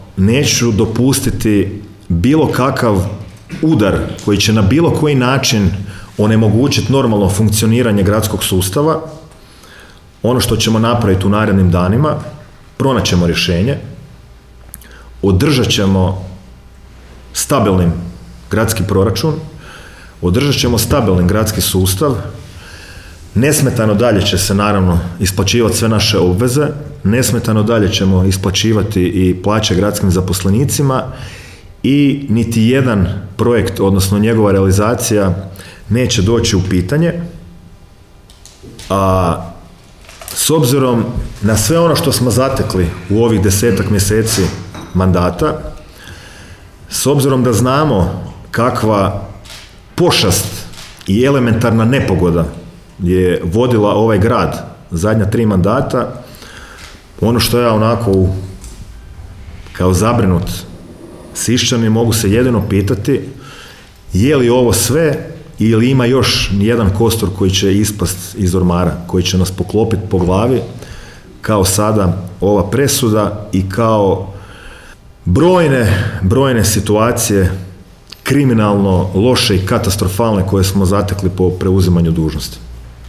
O presudi je, na tiskovnoj konferenciji održanoj u sisačkoj gradskoj upravi, govorio gradonačelnik Domagoj Orlić.